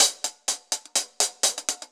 Index of /musicradar/ultimate-hihat-samples/125bpm
UHH_AcoustiHatC_125-03.wav